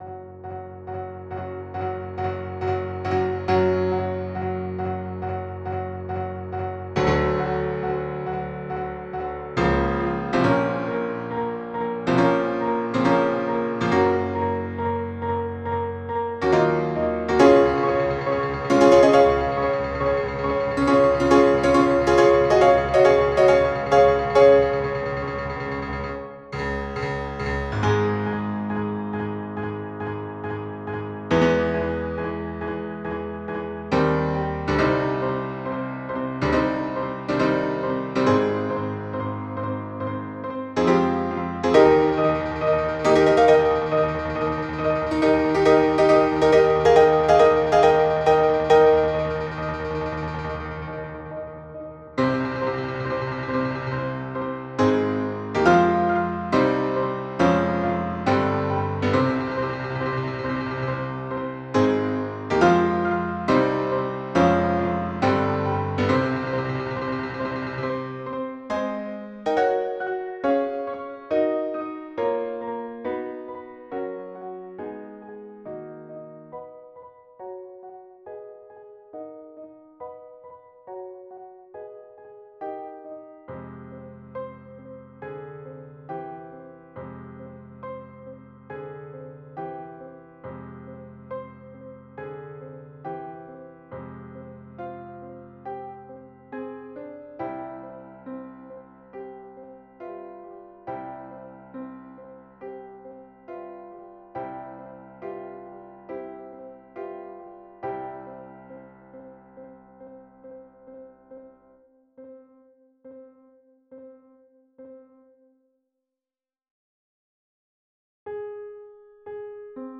Symphony No. 8 - Finale_Haas: Arranged for FOUR Pianos
Bruckner-Symphony-No-8-Finale-arr-for-4-pfs.flac